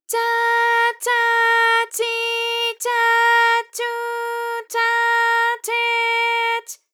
ALYS-DB-001-JPN - First Japanese UTAU vocal library of ALYS.
cha_cha_chi_cha_chu_cha_che_ch.wav